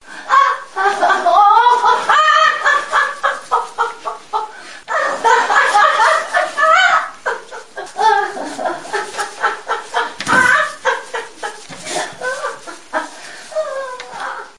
screams2
描述：screaming into a pillow.
标签： female scream pain voice woman
声道立体声